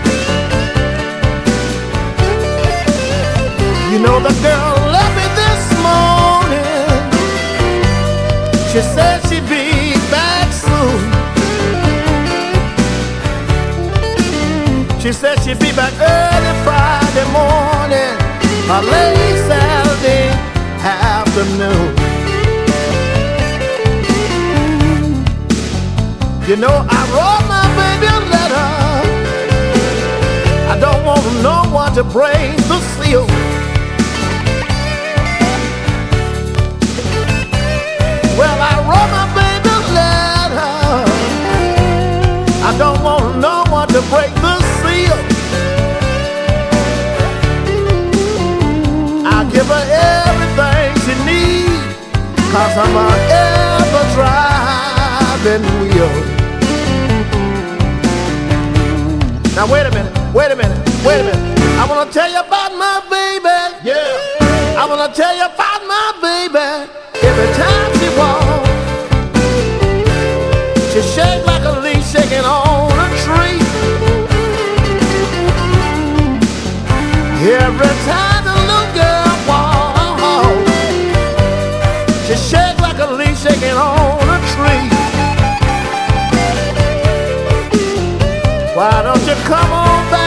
BLUES / RHYTHM & BLUES